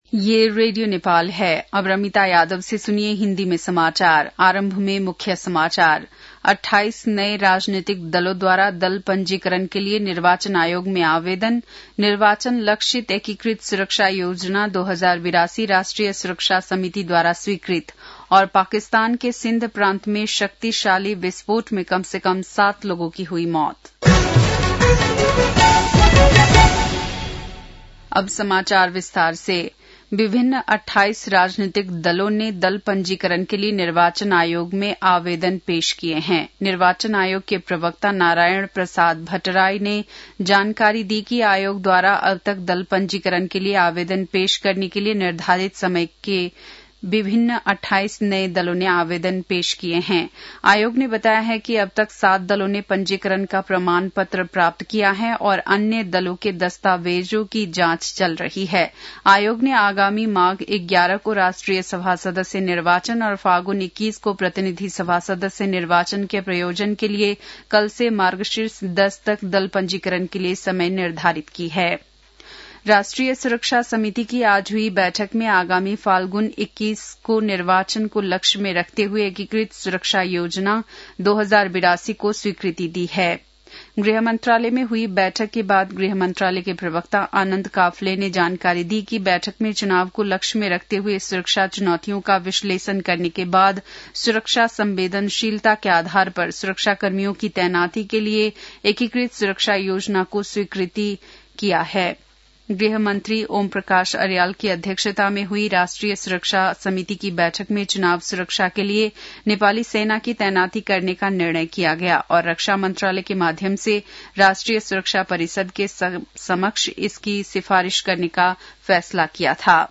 बेलुकी १० बजेको हिन्दी समाचार : ३० कार्तिक , २०८२
10PM-Hindi-NEWS-7-30.mp3